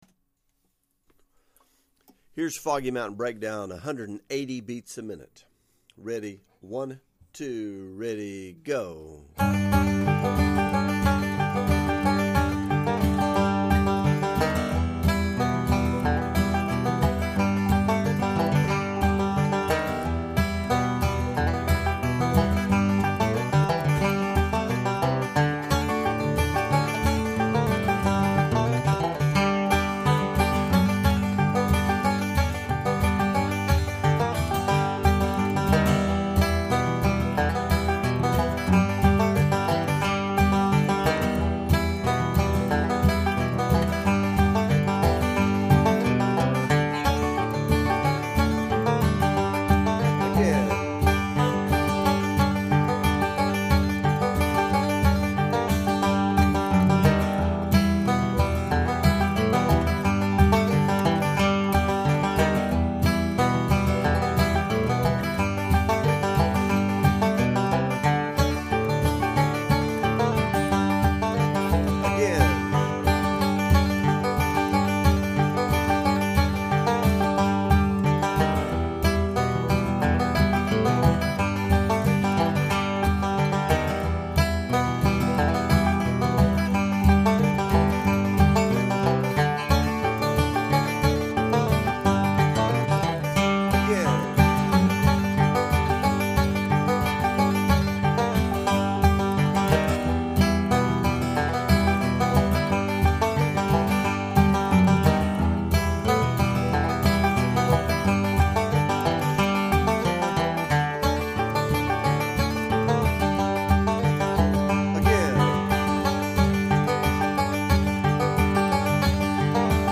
180 bpm